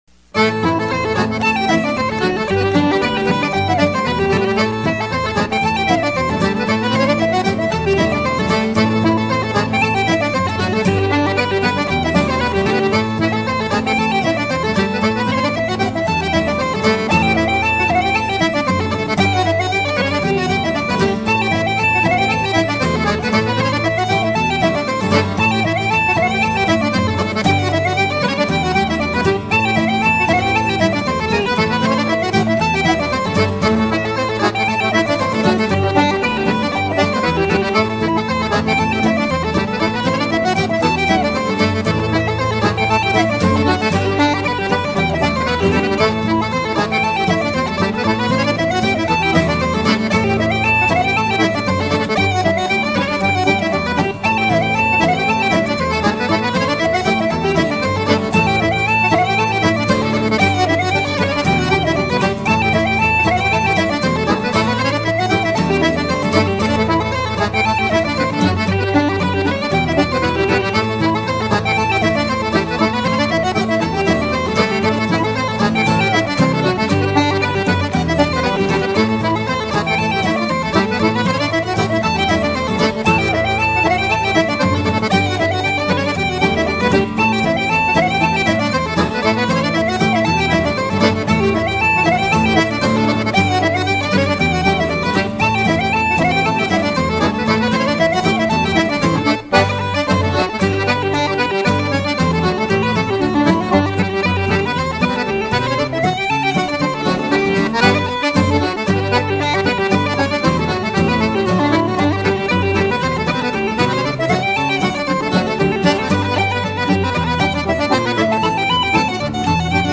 The Old Coppperplate/The Hunters House/The Golden Keyboard (reels)